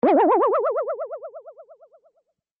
comical_boing